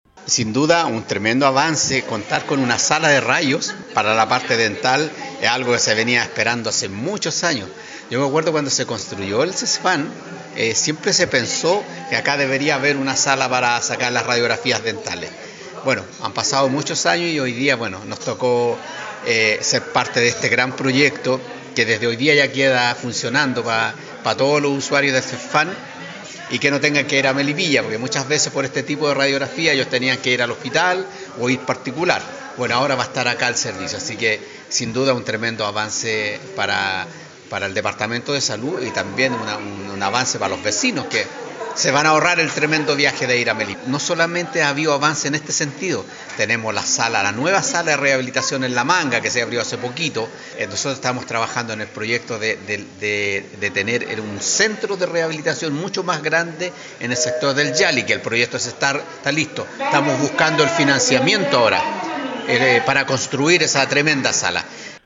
Tras la ceremonia el alcalde de San Pedro destaco el nuevo servicio para los vecinos de la comuna “yo me acuerdo cuando se construyó el CEFAM siempre se pensó que acá debería haber una sala para sacar las radiografías dentales. Han pasado muchos años y hoy día nos tocó ser parte de este gran proyecto que desde hoy día ya queda funcionando para todos los usuarios del CEFAM y que no tengan que ir a Melipilla porque muchas veces por este tipo de radiografías ellos tenían que ir al hospital o ir particular” sentencio.